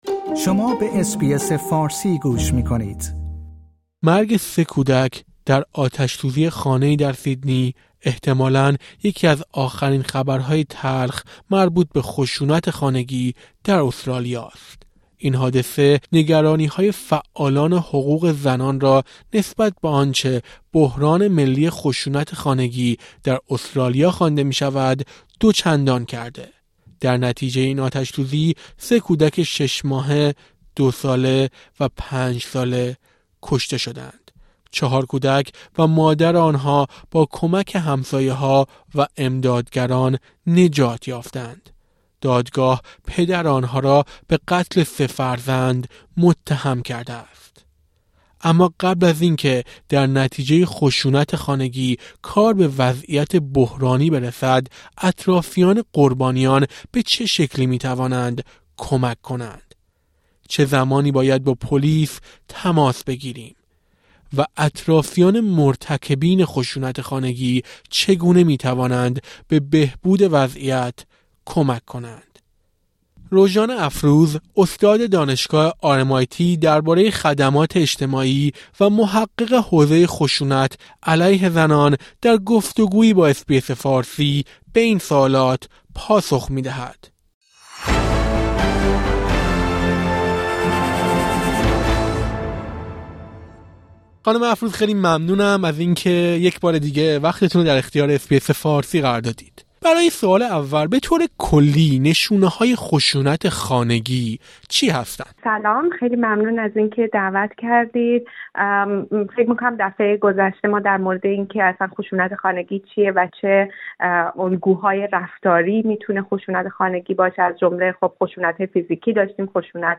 در گفت‌وگویی با اس‌بی‌اس فارسی به این سوالات پاسخ می‌دهد.